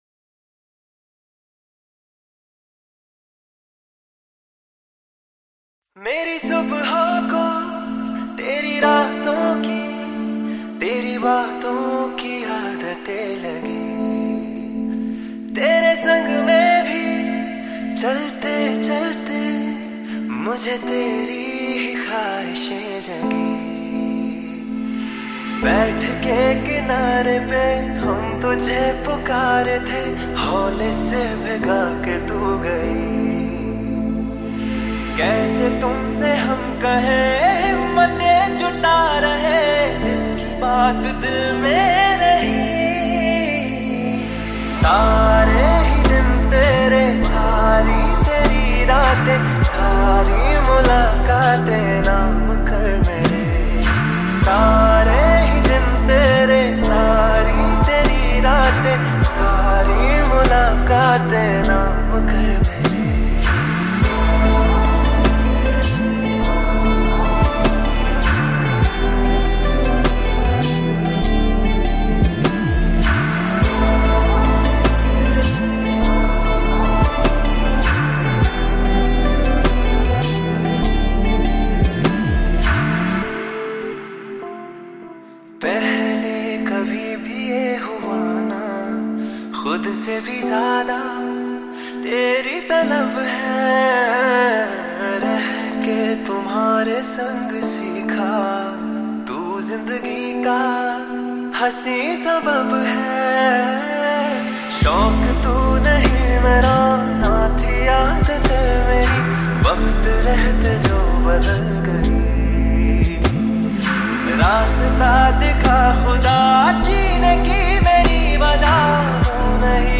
Category New Hindi Songs 2025 Singer(s